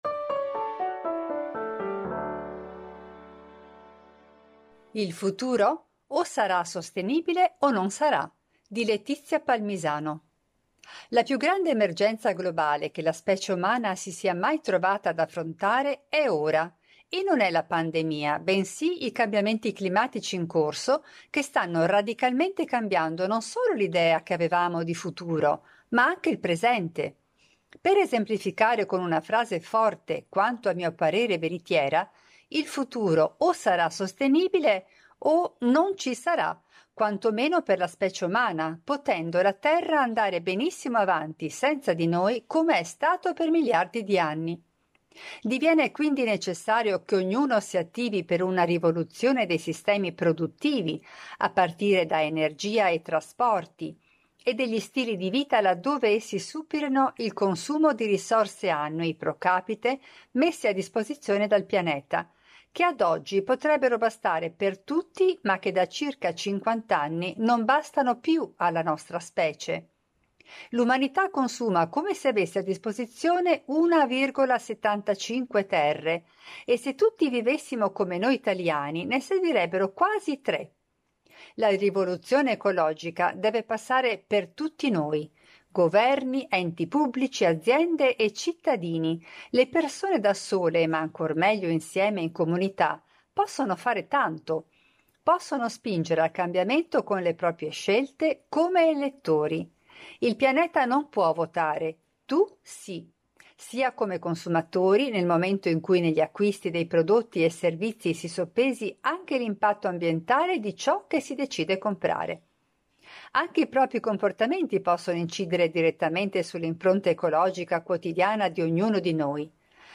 Per ogni numero, ci sarà una selezione di 10 articoli letti dai nostri autori e collaboratori.
Al microfono, i nostri redattori e i nostri collaboratori.